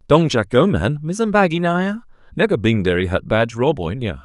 다음은 Parler-TTS의 고니와 아귀의 명대사를 들어보자
지금까지 HuggingFace 극단의 외국인 배우의 한국영화 명대사 열연을 보셨습니다.